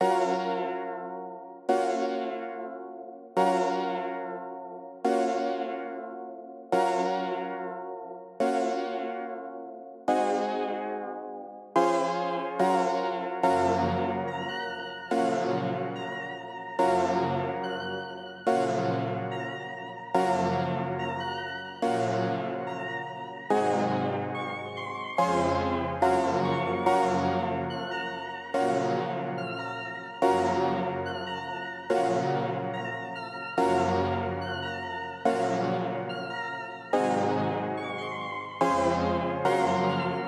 环境的空间陷阱循环
描述：环境和弦是环境和陷阱音乐的理想选择
Tag: 140 bpm Ambient Loops Bells Loops 6.78 MB wav Key : C